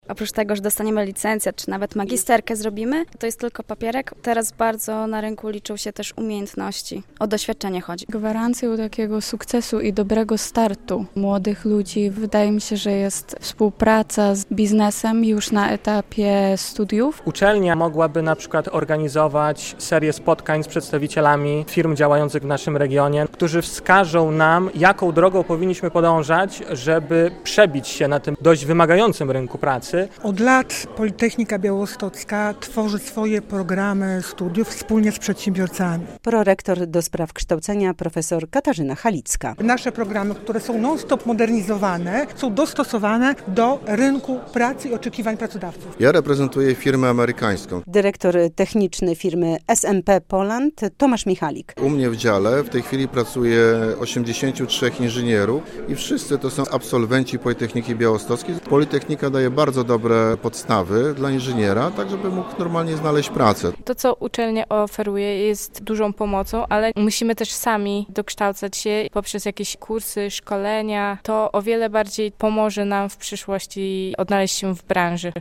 Dyplom dziś już nie wystarcza - debata na politechnice